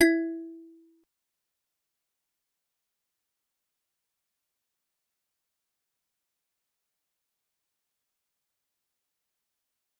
G_Musicbox-E4-pp.wav